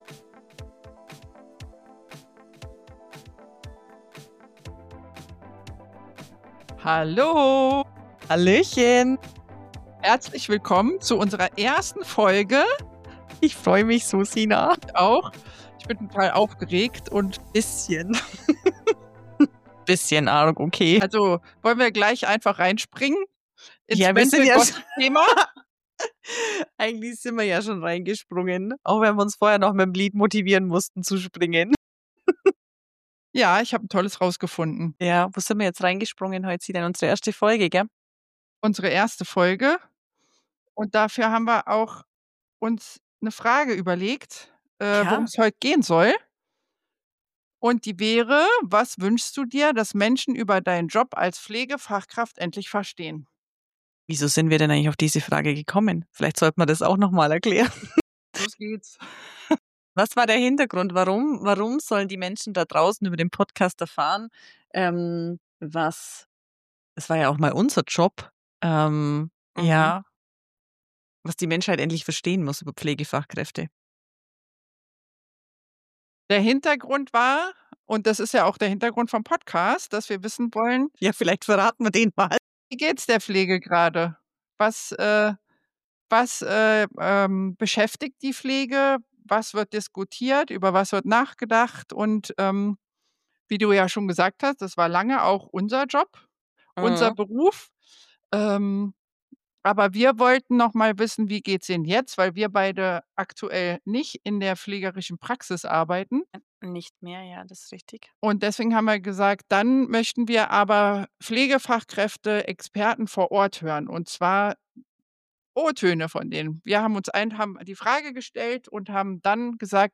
Wir hören O-Töne direkt aus der Praxis, sprechen offen über mentale Gesundheit und die täglichen Herausforderungen...